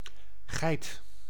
Ääntäminen
IPA: [buk]